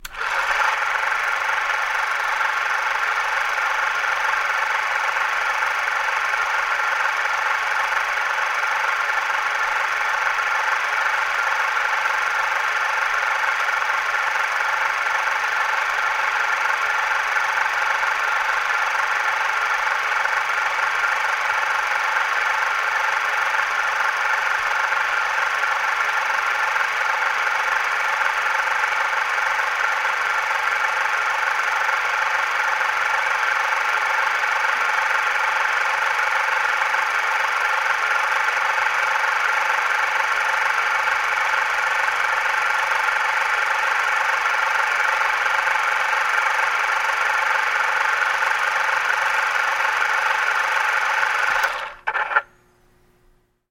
Звуки кинохлопушки
Старинная кинокамера начала снимать черно-белое кино